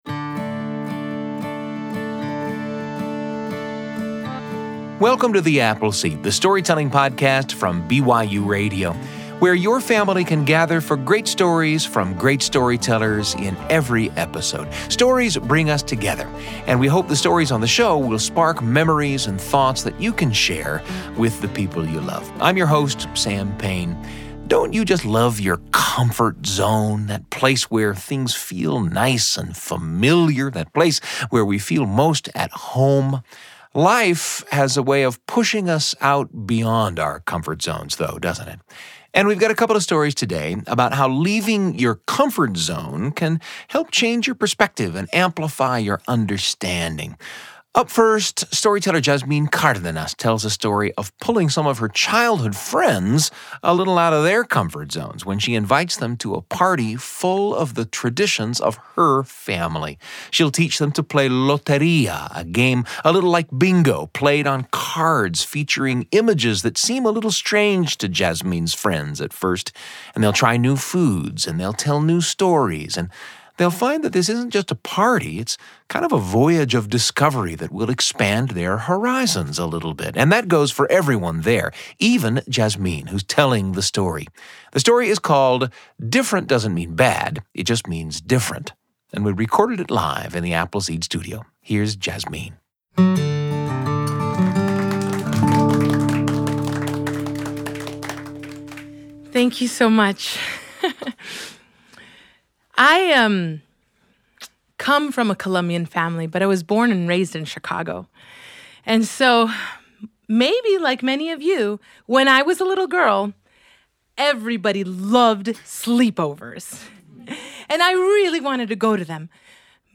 Gather your family for great stories from great storytellers.
… continue reading 290 Episoden # BYUradio # Tall Tales # Bedtime Stories # Audio Drama